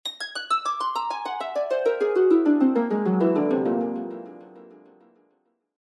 Заклинания тают в воздухе